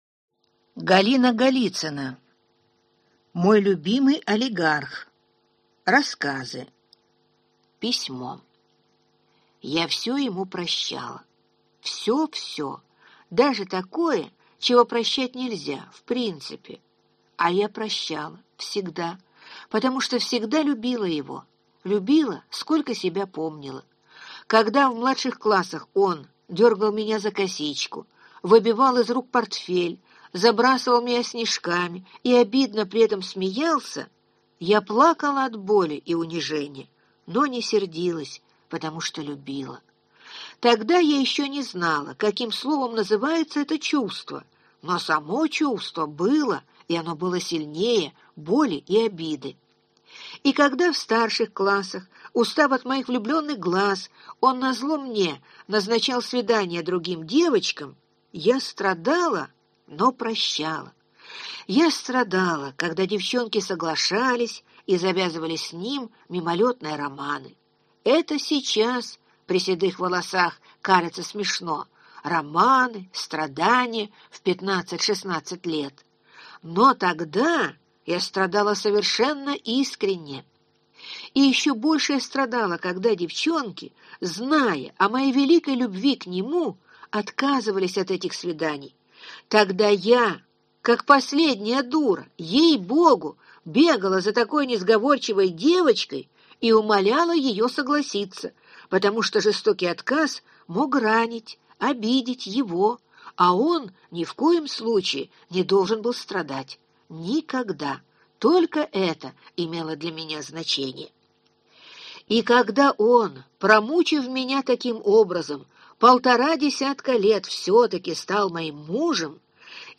Аудиокнига Мой любимый олигарх | Библиотека аудиокниг